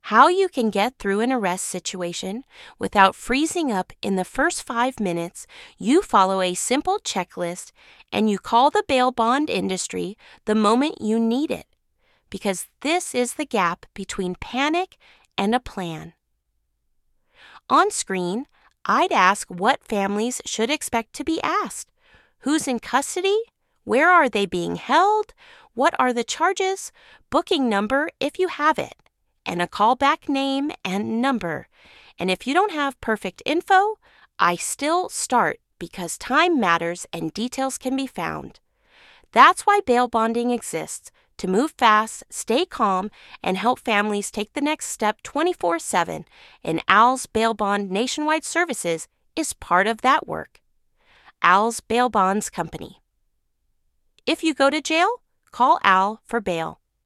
Voiceover video with on-screen prompts showing the exact questions families should expect (who is in custody, where, charges, booking number if available, contact info). Emphasize speed, compassion, and that callers don’t need to have “perfect info” to start. Visuals: clean animated cards in black/gold, quick transitions, reassuring tone.